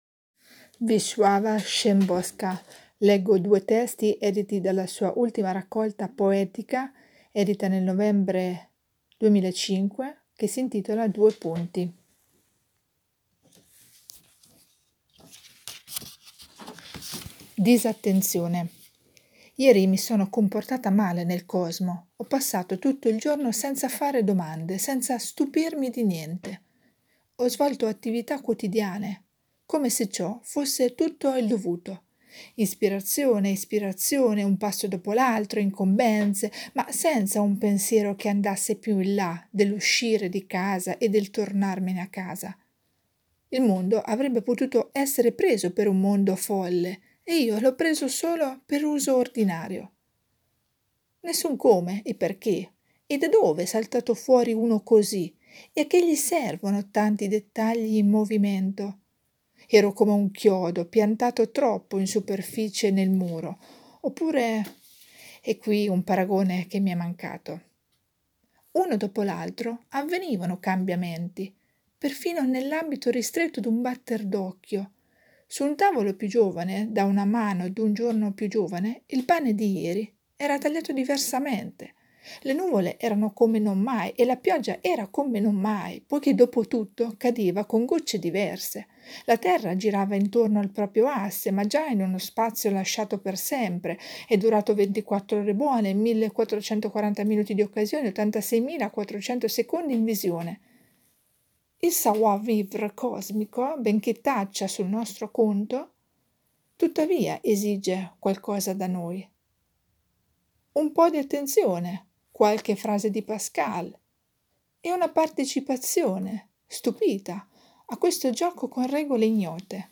lettura della Szymborska